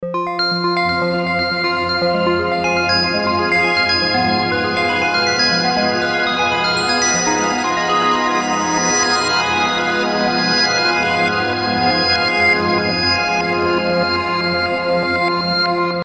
Magical Dreamy Sparkle Arpeggio Music Loop
Genres: Synth Loops
Tempo: 120 bpm